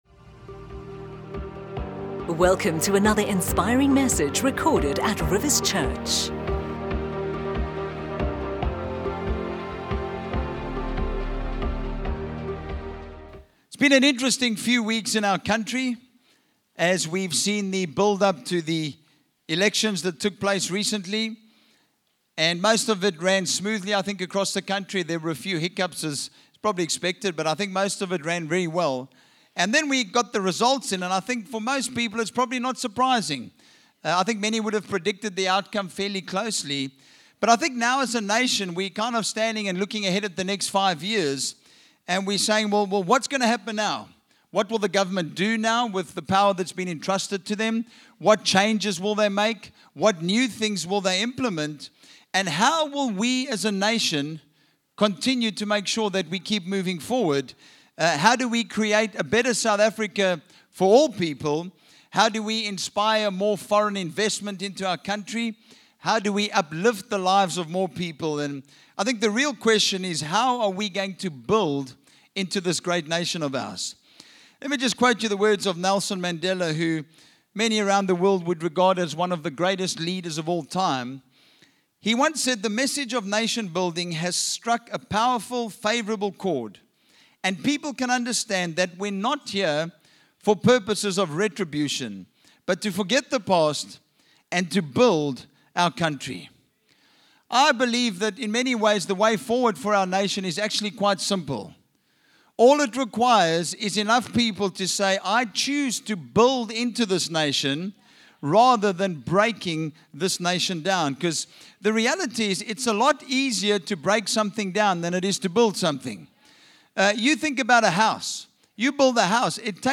You can download our weekend messages for free!